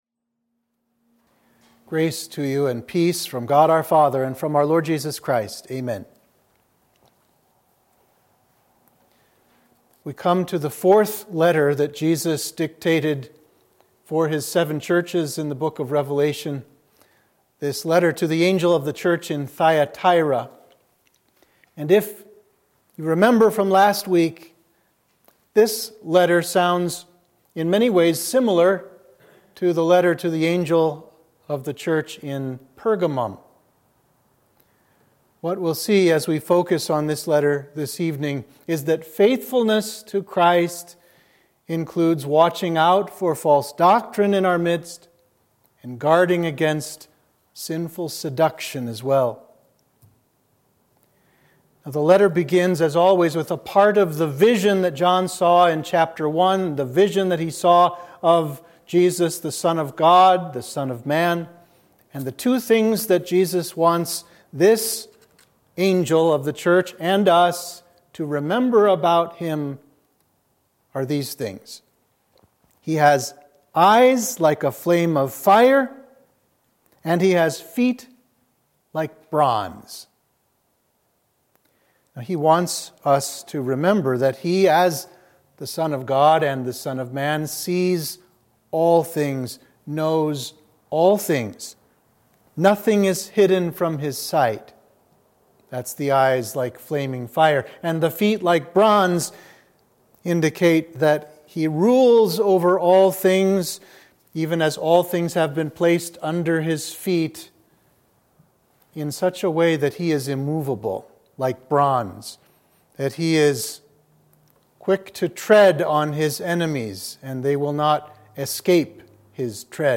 Sermon for Midweek of Sexagesima